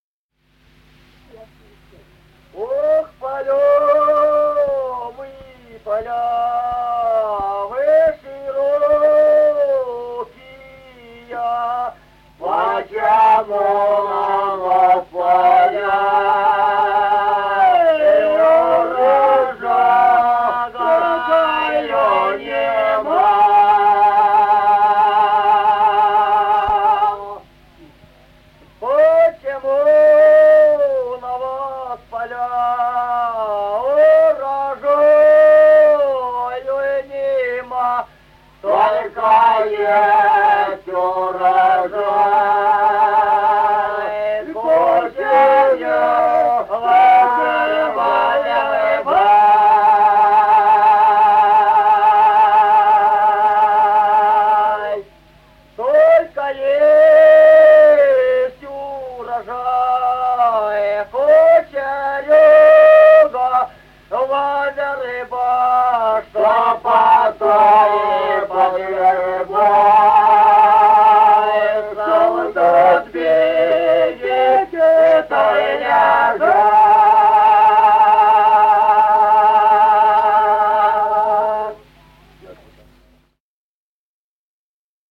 Музыкальный фольклор села Мишковка «Ох, поля, вы поля», лирическая.